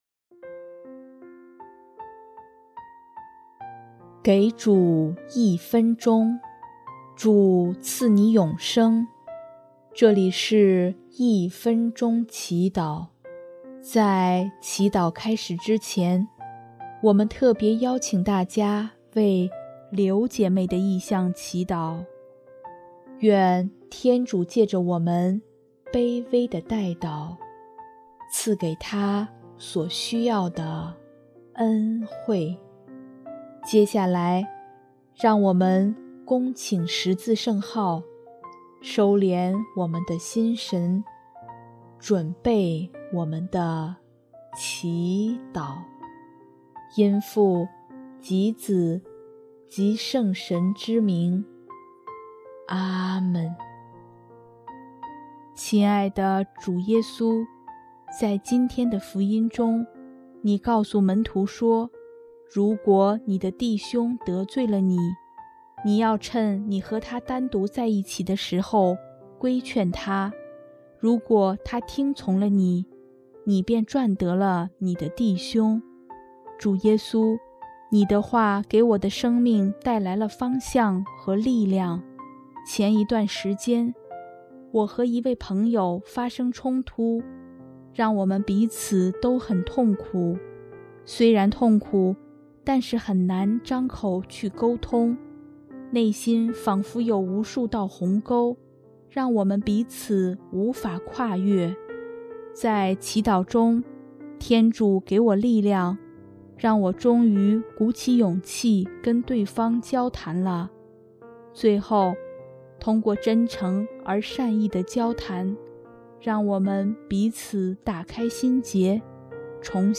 音乐：第二届华语圣歌大赛获奖歌曲《请你牵起我的手》